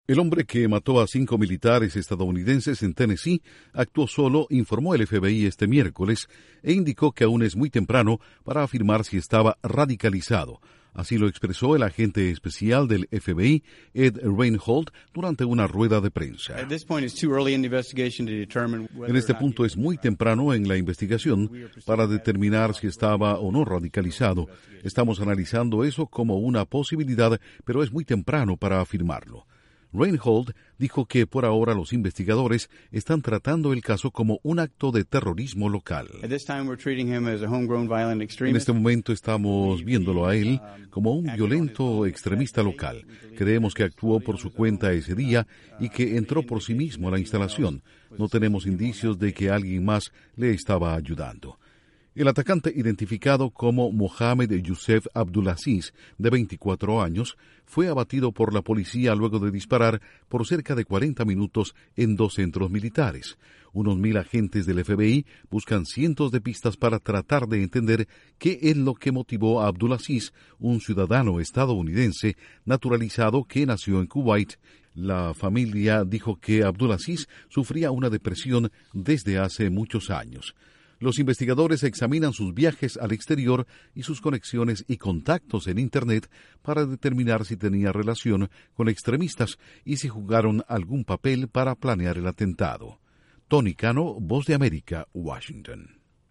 El FBI da a conocer que el hombre que mató a cinco militares en Estados Unidos actuó solo. Informa